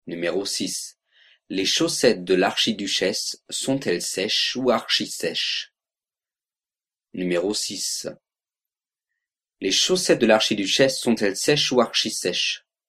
06 Virelangue